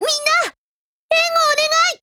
贡献 ） 协议：Copyright，其他分类： 分类:少女前线:UMP9 、 分类:语音 您不可以覆盖此文件。